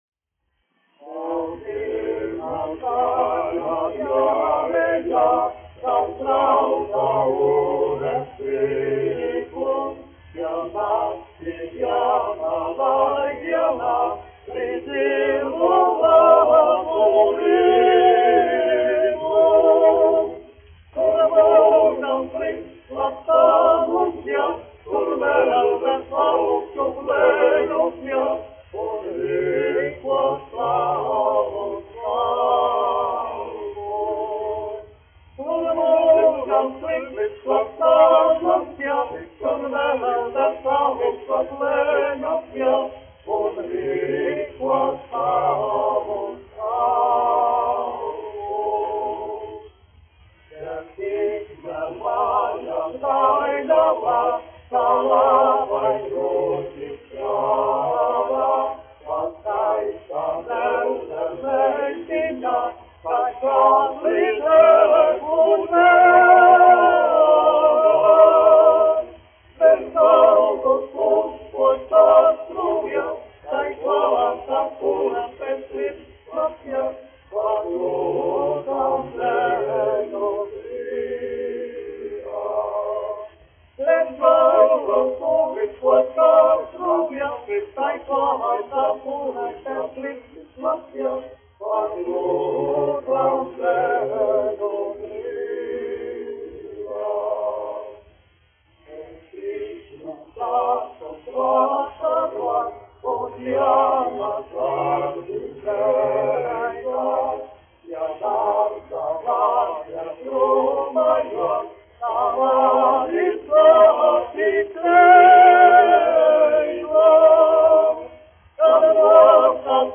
1 skpl. : analogs, 78 apgr/min, mono ; 25 cm
Kori (vīru)
Skaņuplate